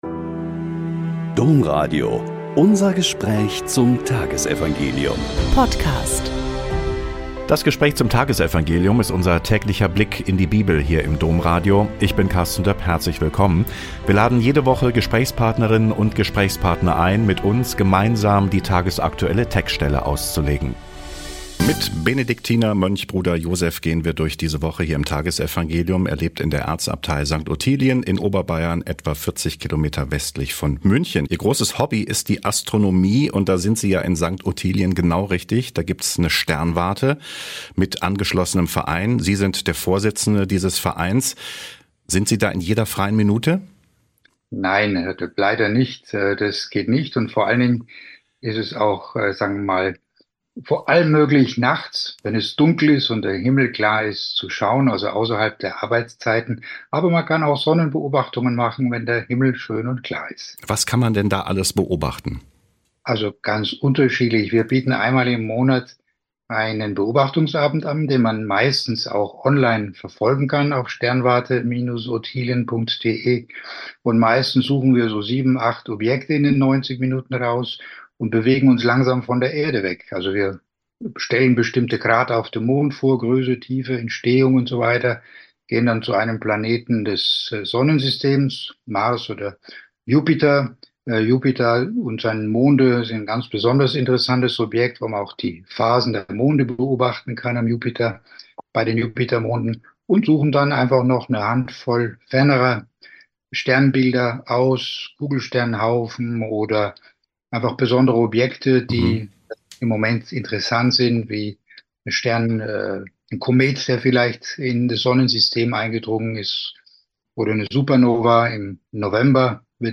Lk 6,27-38 - Gespräch